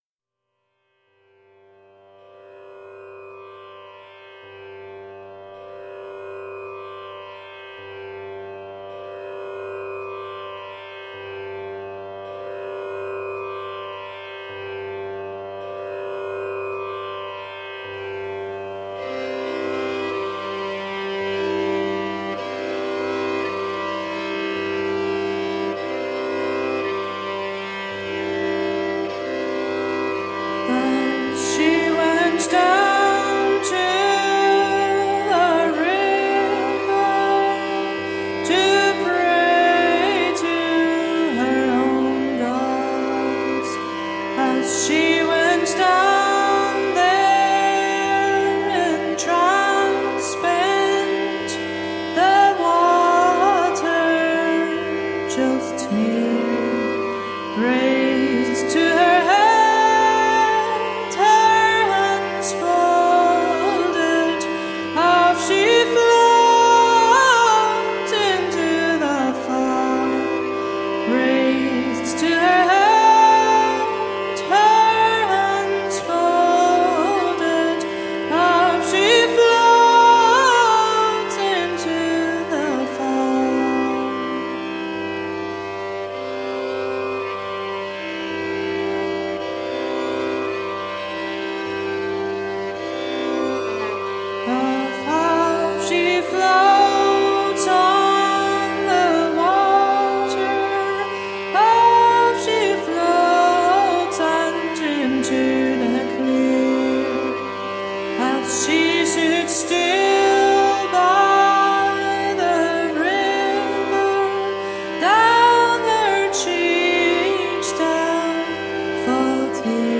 Tagged as: World, Folk, World Influenced